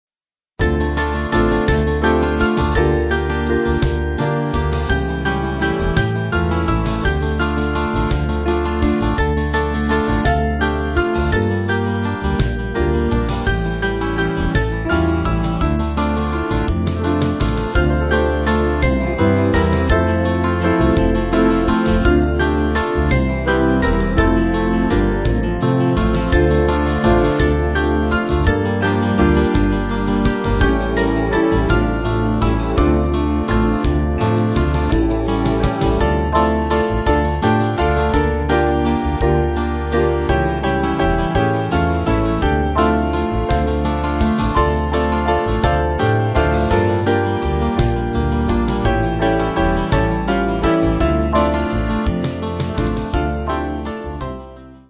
Small Band
(CM)   4/Dm-Ebm